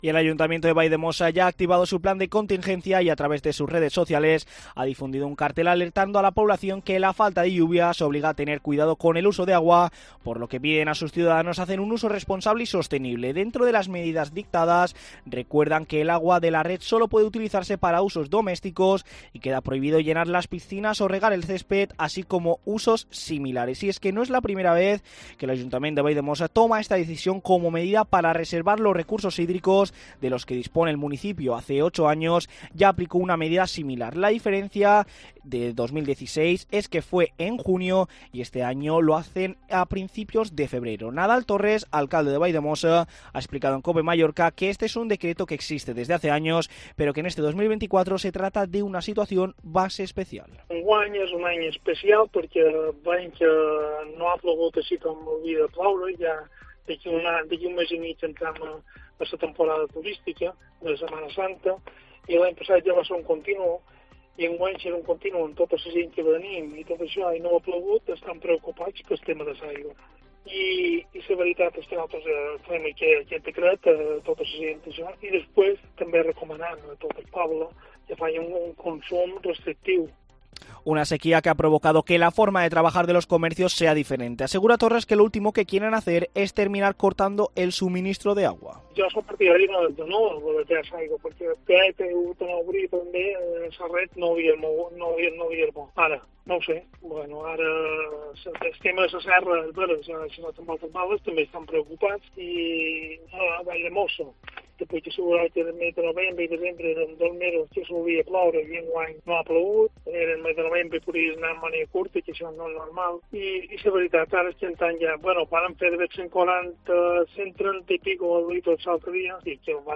Nadal Torres, alcalde de Valldemossa ha explicado en COPE Mallorca que "este es un decreto que existe desde hace años, pero que en este 2024 se trata de una situación especial."